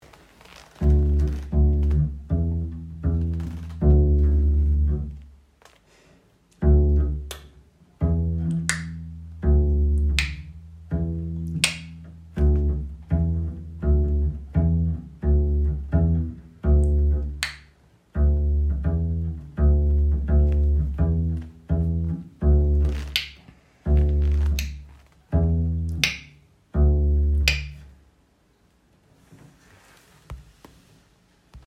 pizzicato - gezupft